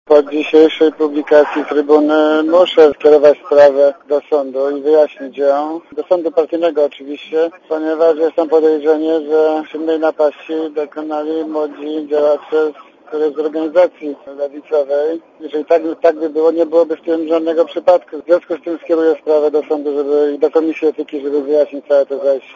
Mówi Marek Dyduch (90Kb)